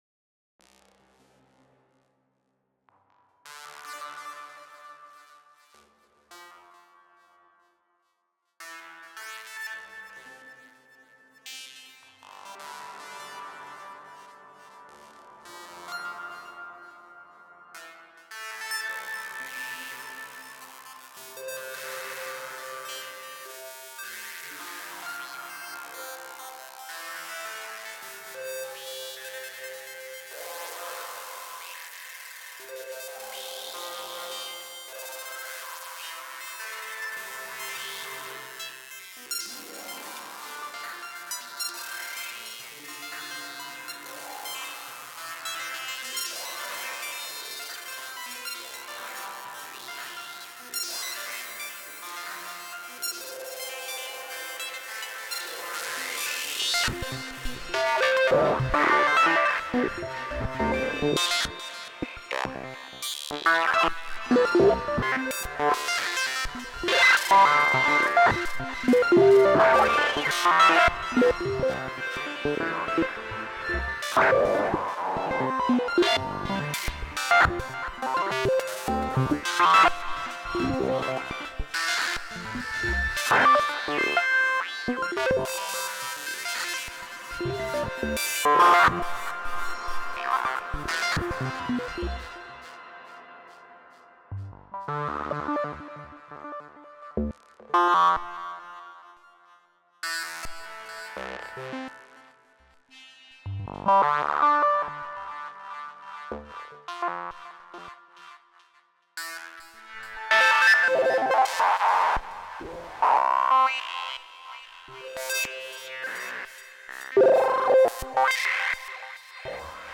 First SY CHIP attempt - a single pattern in Song mode, lots of FX block: